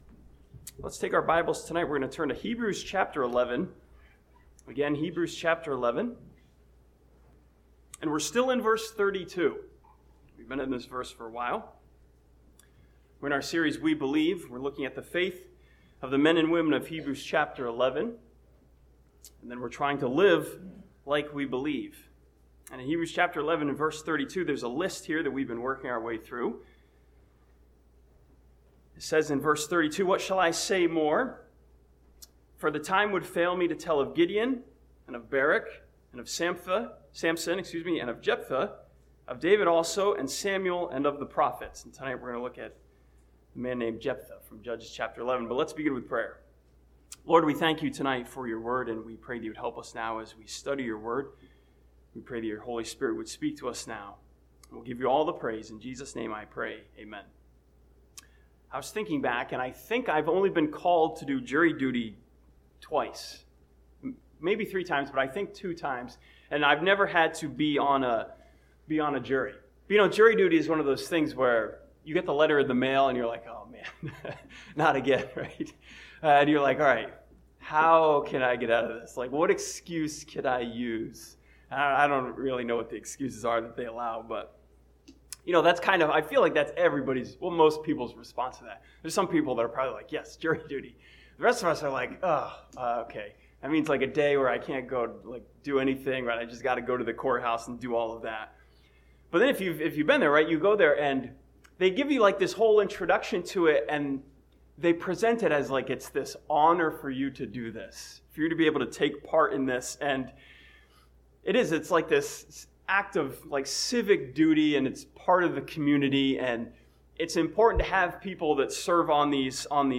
This sermon from Hebrews chapter 11 and Judges 11 studies the story of Jephthah and his faith in God as the Judge.